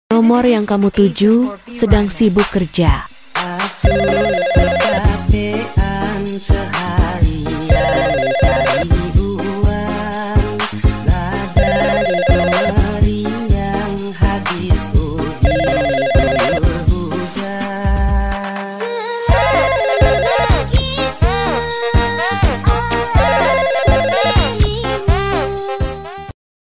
Genre: Nada dering panggilan